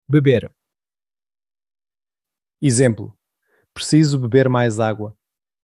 Pronunciación de la palabra